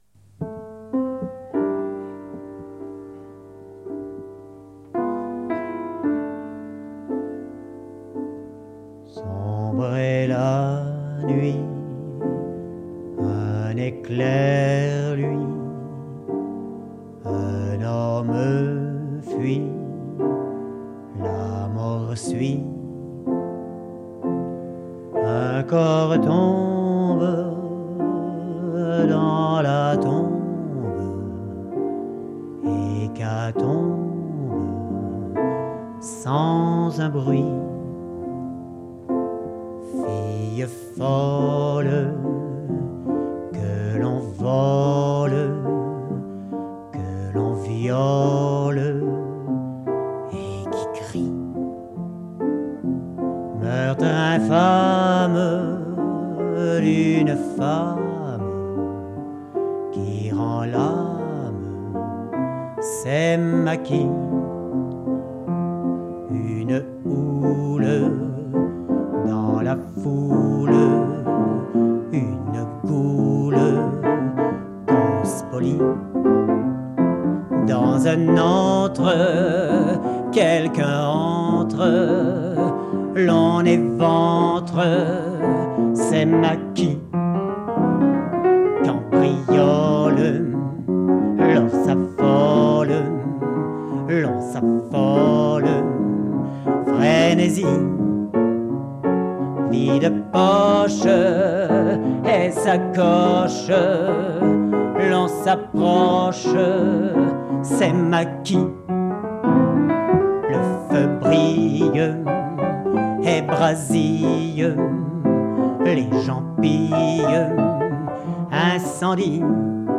Enregistr�e en public (1985)